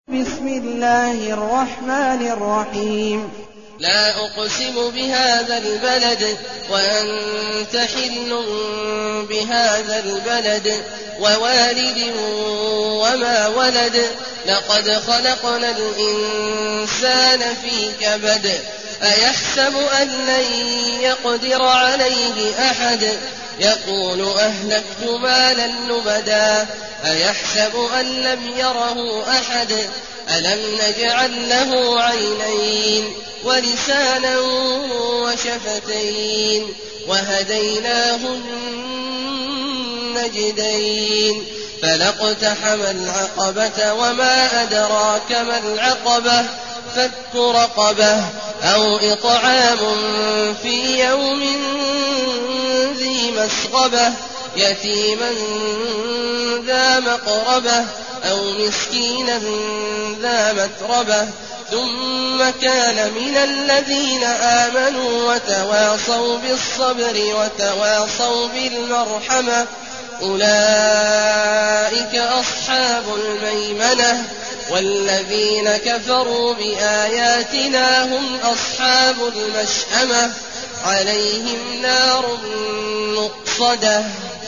المكان: المسجد النبوي الشيخ: فضيلة الشيخ عبدالله الجهني فضيلة الشيخ عبدالله الجهني البلد